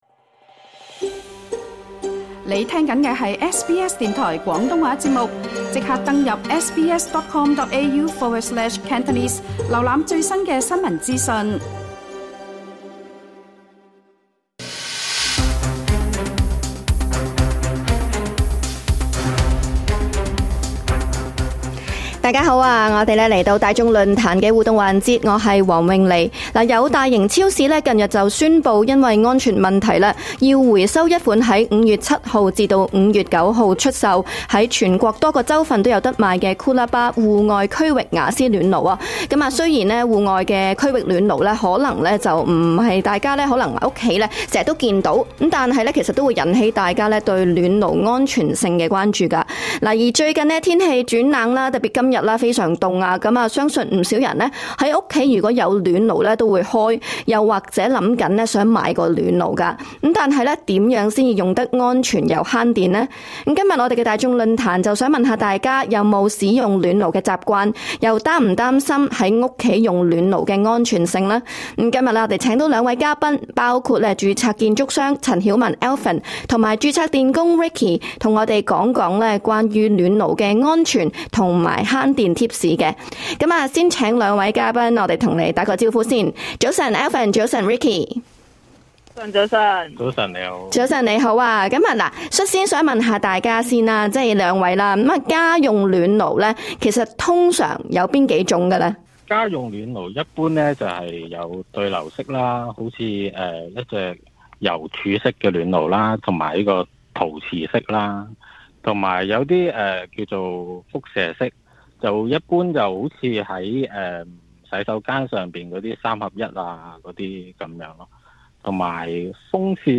今集《大眾論壇》就問問聽眾們是否有使用暖爐的習慣？